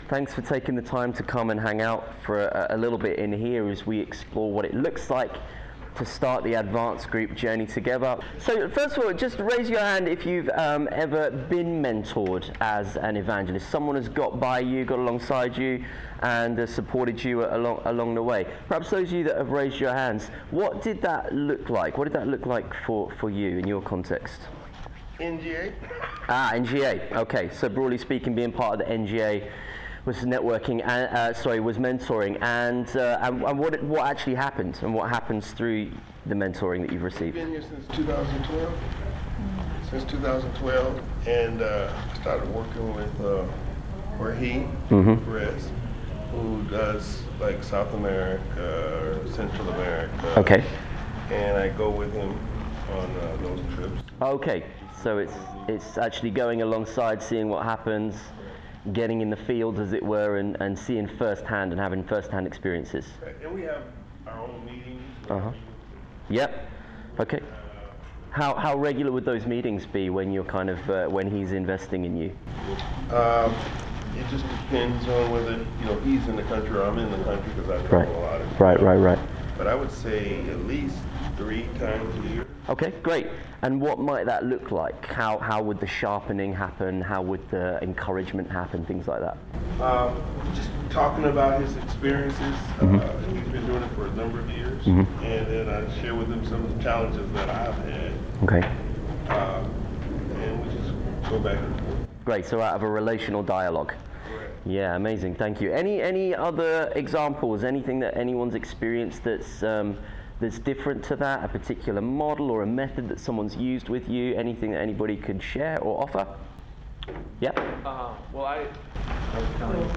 Advance groups are designed to equip, encourage, and empower evangelists through monthlymentoring sessions. In this workshop